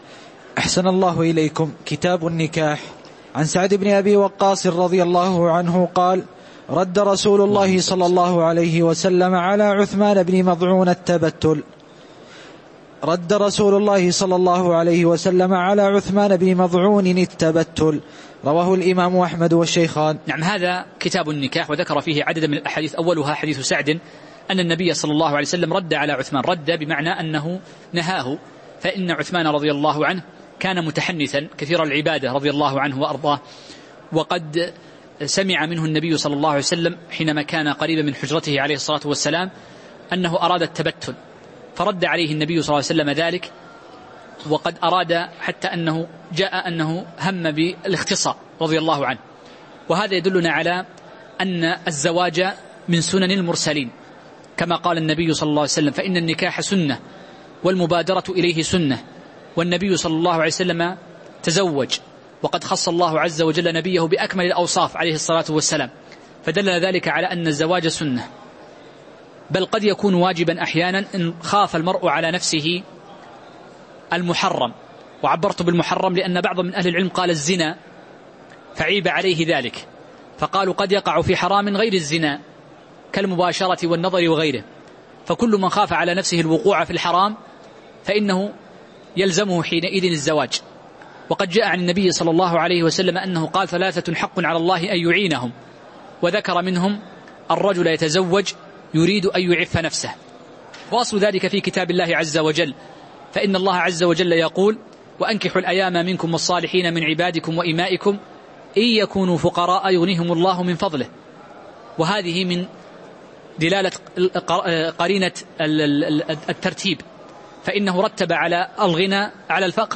تاريخ النشر ١ شعبان ١٤٤٠ هـ المكان: المسجد النبوي الشيخ